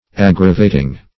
Aggravating \Ag"gra*va`ting\, a.